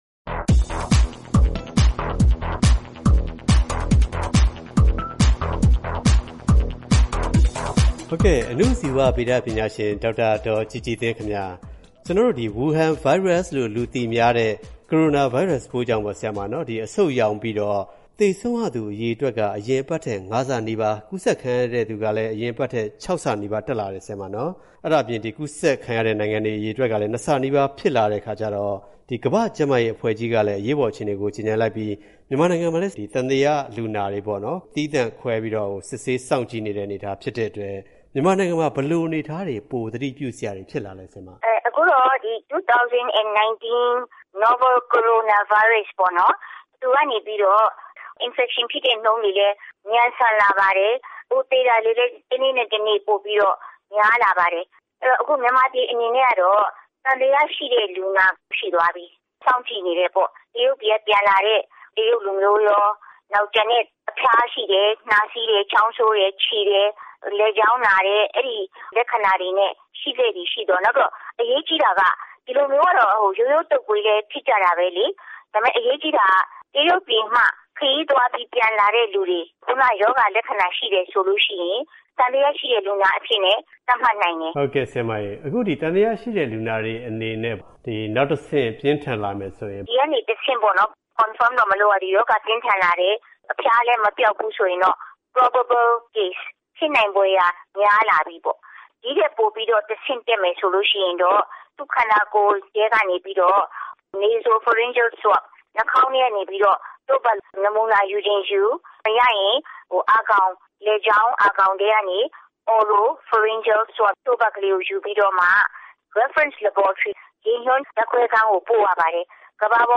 ဆက်သွယ်မေးမြန်းတင်ပြထားပါတယ်။